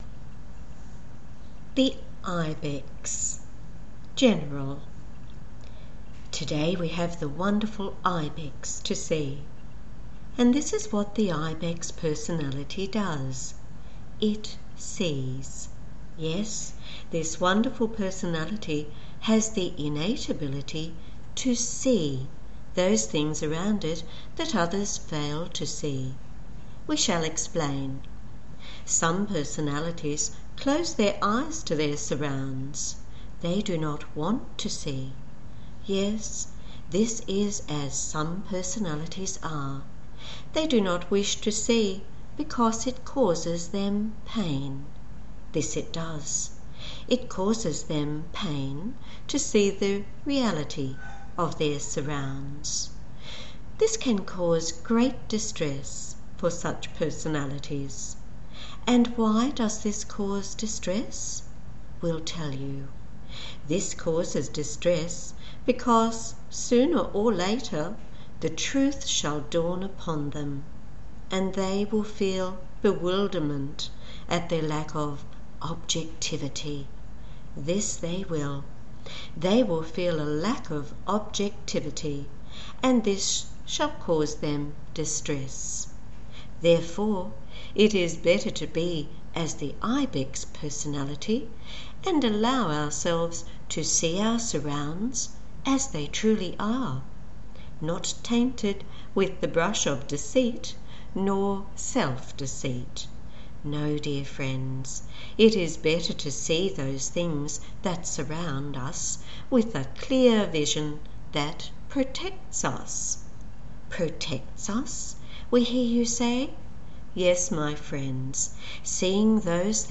Go right ahead; if that’s what you want to do I’ll enjoy picturing you capturing the Aussie drawl whilst tucked up in your sanctuary….!!! :)